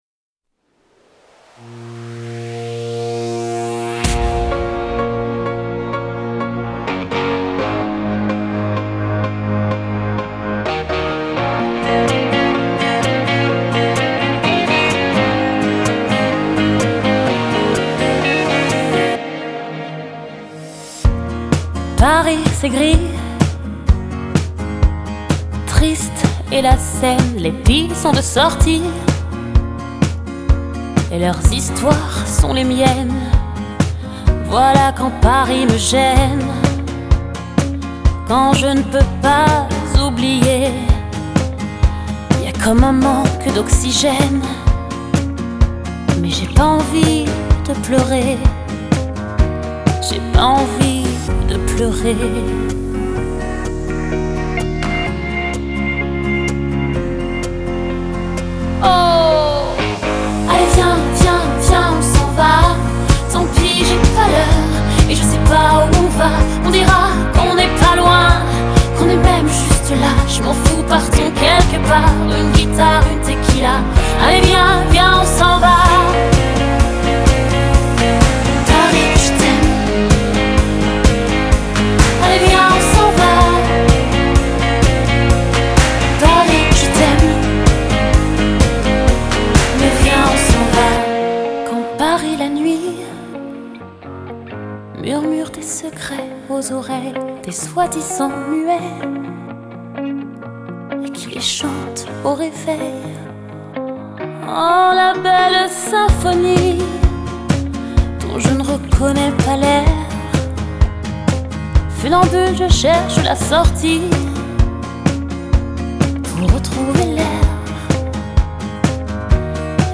Genre: Pop, Dance, R&B, Soul, French